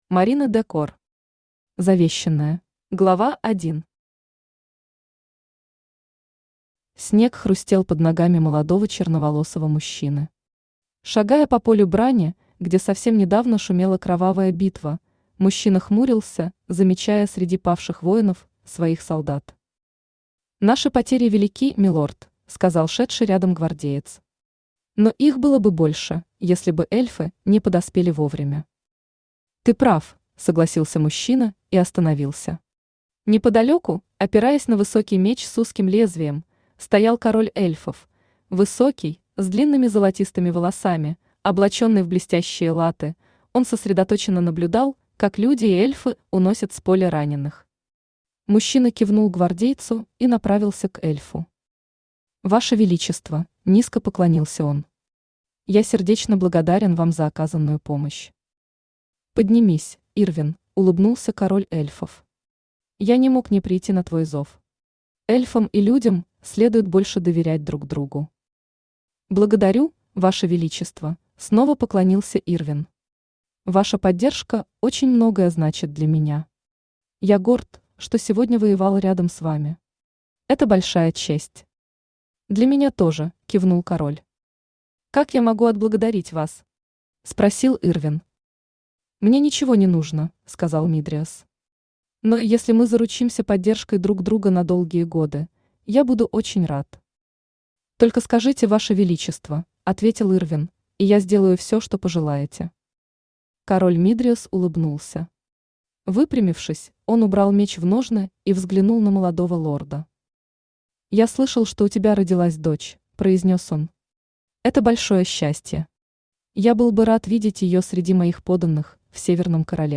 Аудиокнига Завещанная | Библиотека аудиокниг
Aудиокнига Завещанная Автор Марина Де Кор Читает аудиокнигу Авточтец ЛитРес.